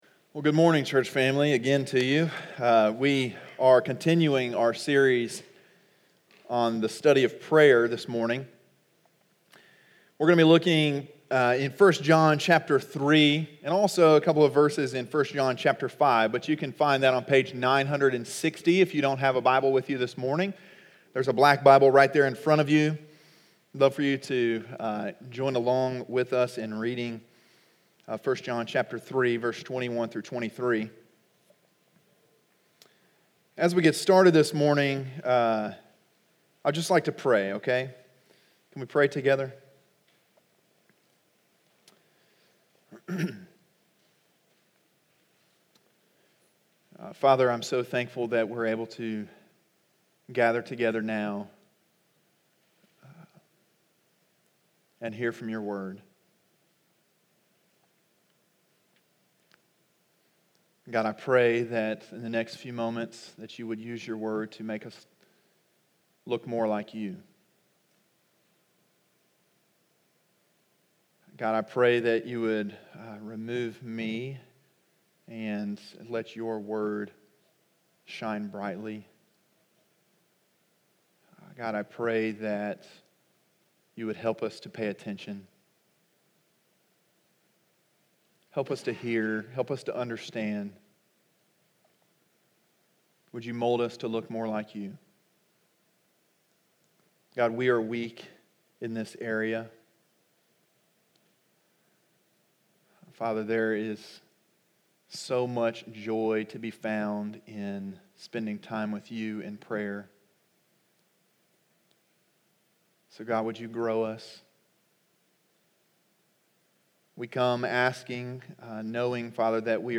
sermon1.19.20.mp3